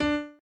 b_pianochord_v100l8o5d.ogg